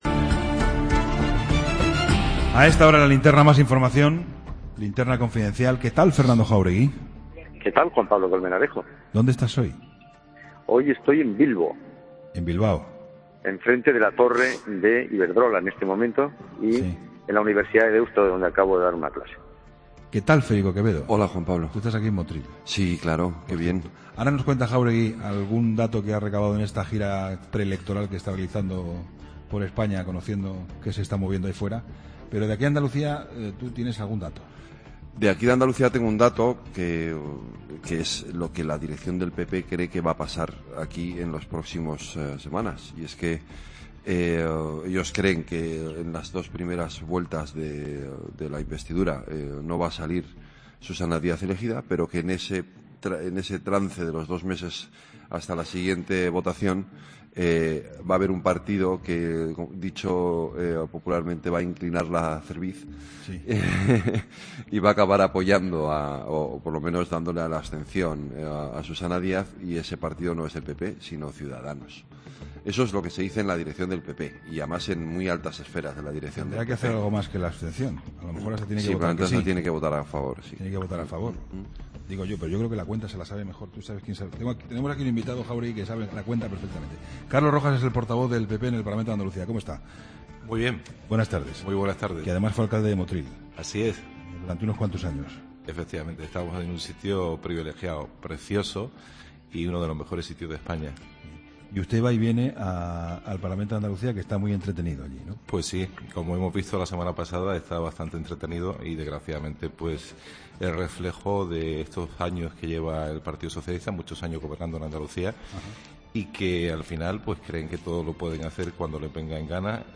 Entrevista a Carlos Rojas, portavoz del PP en el Parlamento andaluz